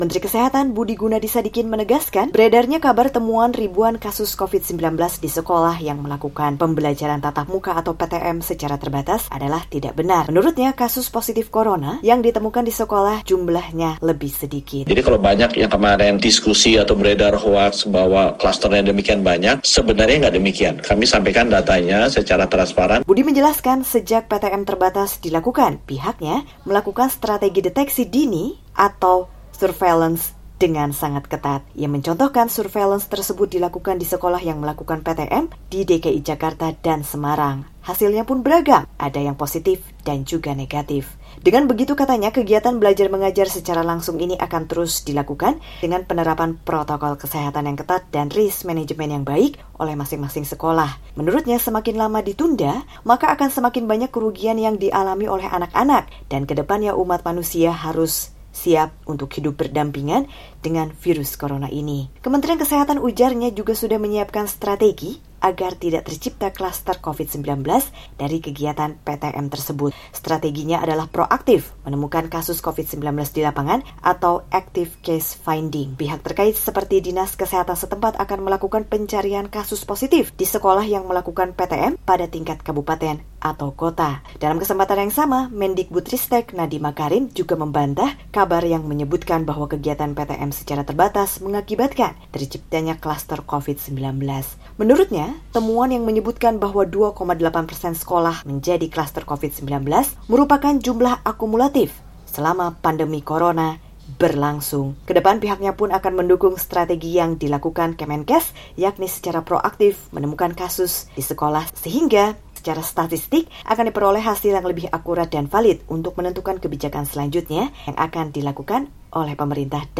Menkes Budi Gunadi Sadikin dalam telekonferensi pers usai Ratas di Jakarta, Senin (27/9) menepis kabar terciptanya ribuan kasus Corona di sekolah akibat adanya PTM (VOA)